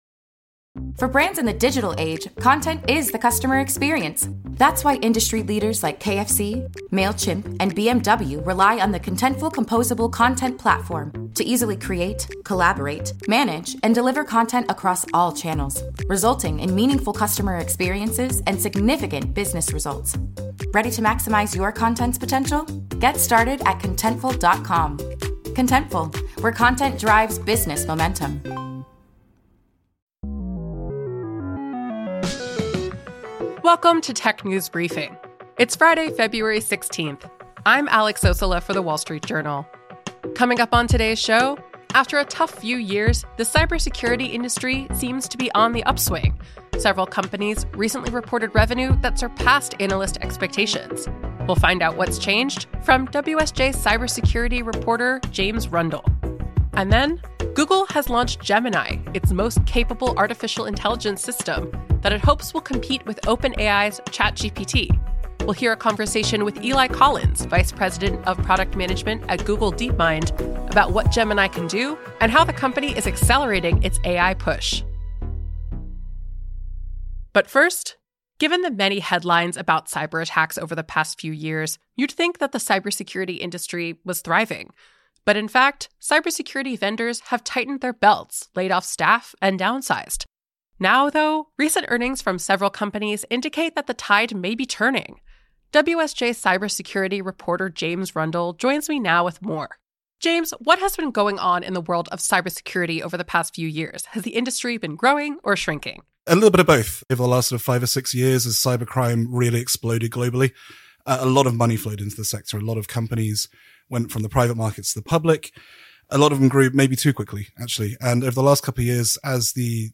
Their conversation was recorded at WSJ’s CIO Network Summit on February 13.